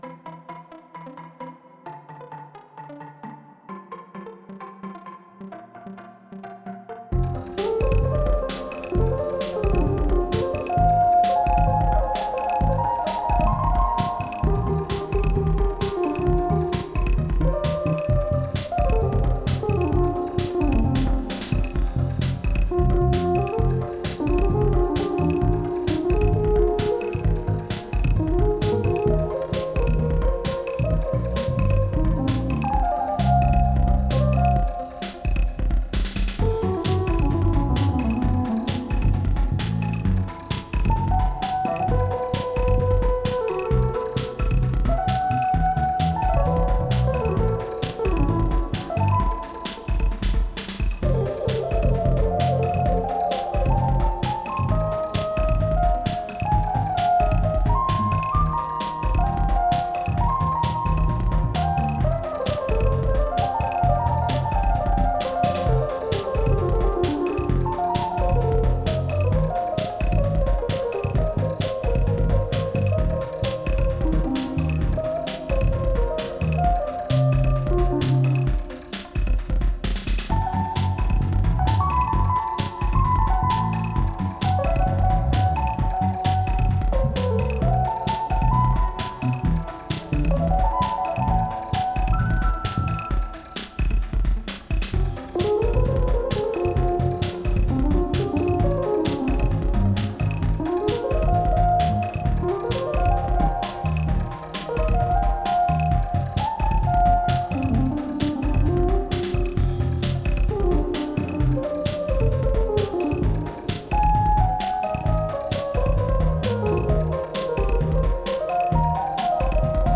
Electric Bass, Drums, Electric Piano, Synths
Genre: Hip-Hop